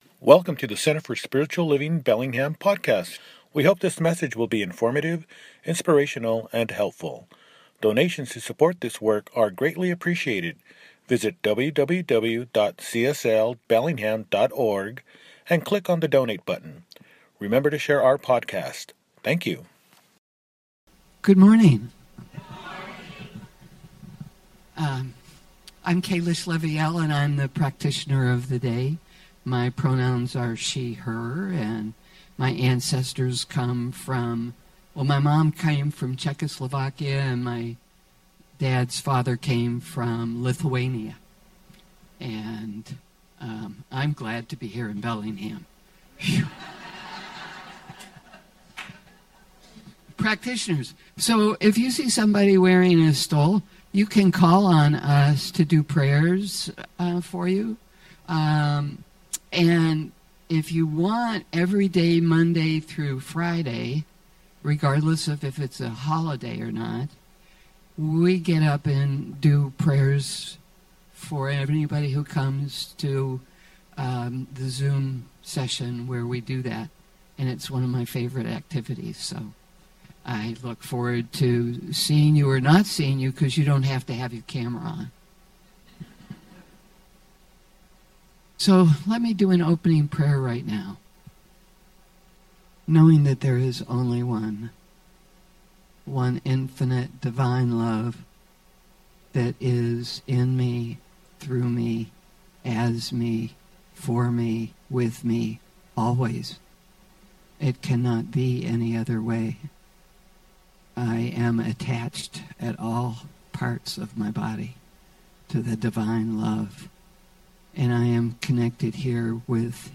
Outer Personal: Walking the Edge of Life – Celebration Service